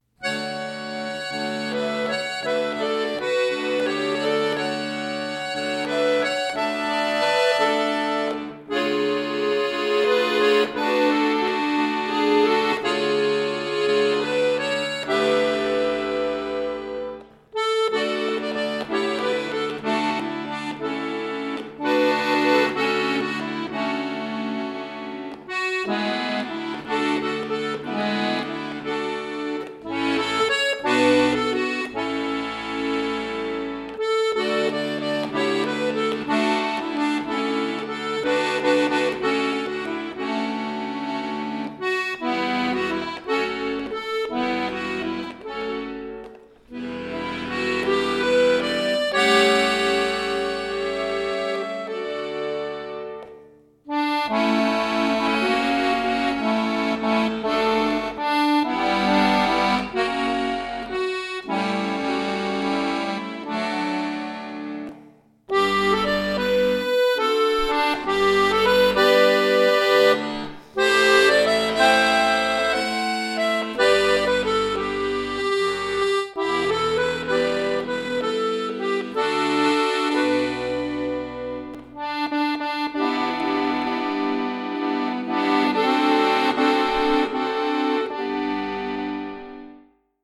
arrangiert für Akkordeon solo
Klassisch